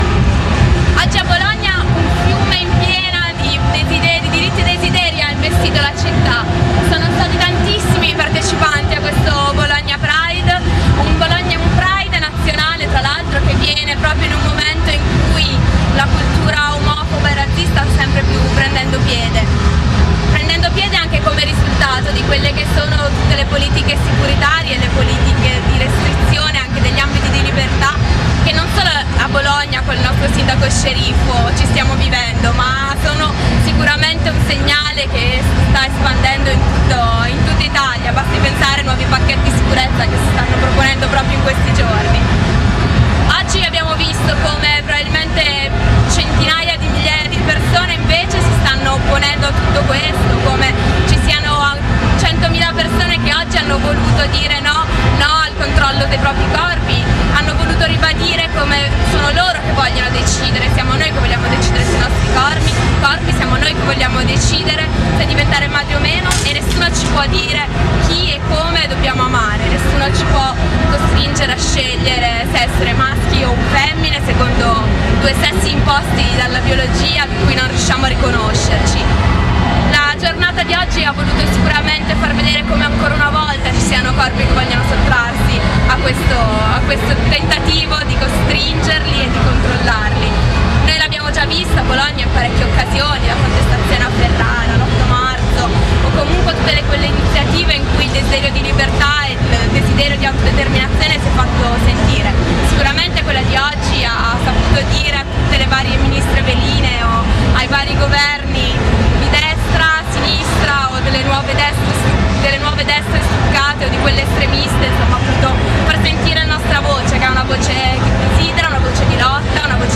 GLBTQ Pride 2008
Raccogliamo qualche valutazione della giornata.